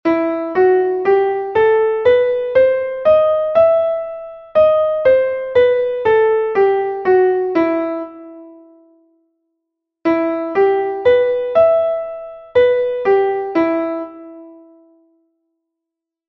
Melodía 2/4 en Mi m
Escala e arpexio:
escala_arpegio_mi_menor_harmonica.mp3